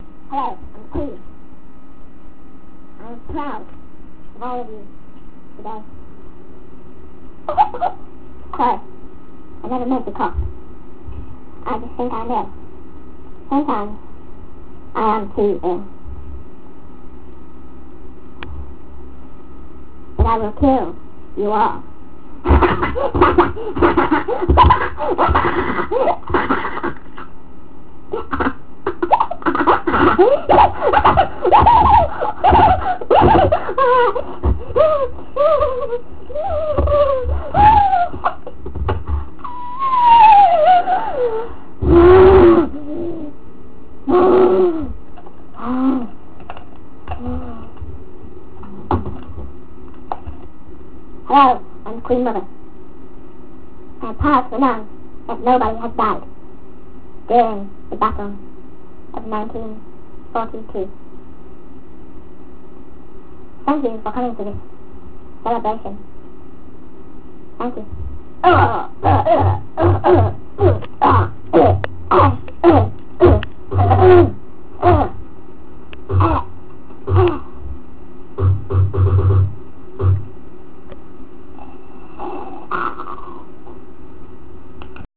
The great Queen's speeches.
Queens speech (volume 1)